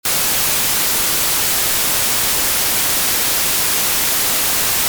A.11 Rumore Bianco Triangolare
rumore-bianco-triangolare.mp3